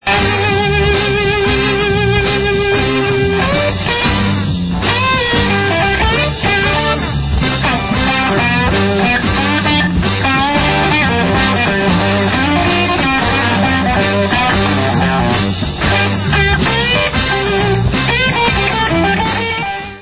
Stereo Version